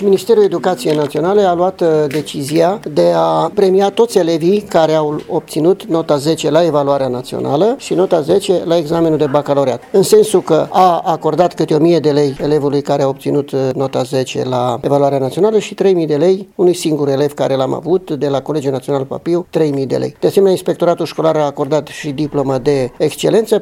Inspectorul școlar general al județului Mureș, Ioan Macarie: